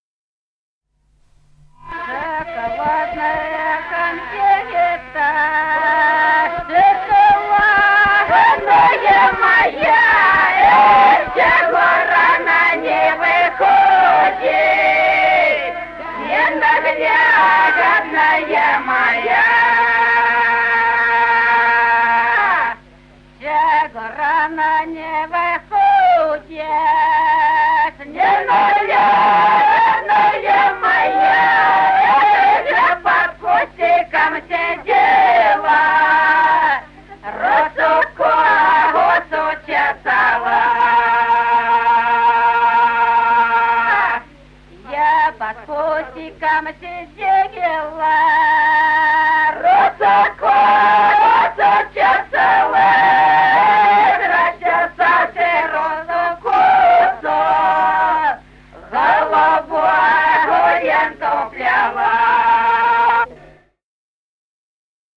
Песни села Остроглядово. Шоколадная конфетка И0443-08